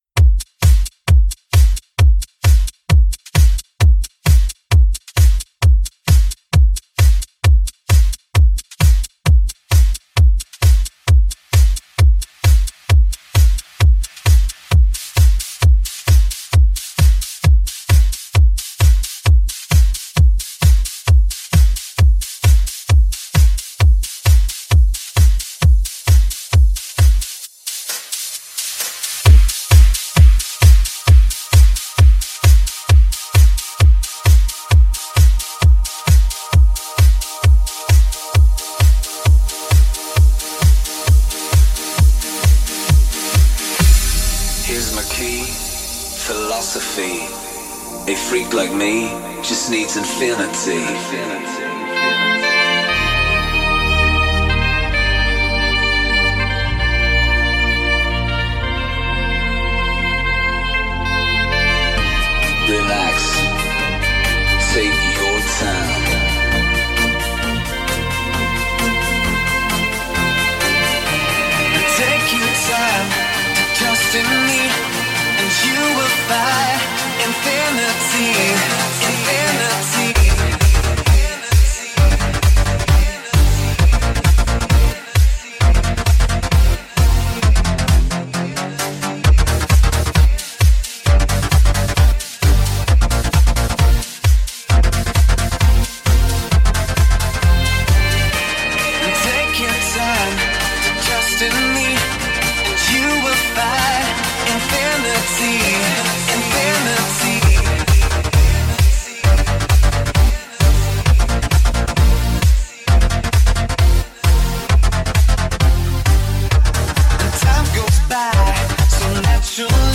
Old School tunes but with a new school feel.